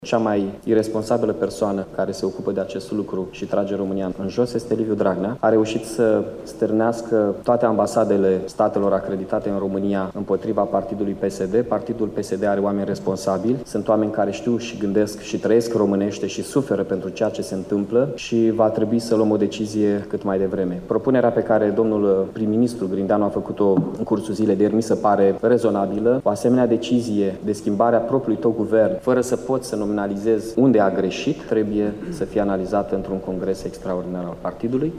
Primarul Iaşului, Miha Chirica, a solicitat astăzi, într-o conferinţă de presă, un congres extraordinar al Partidului Social Democrat.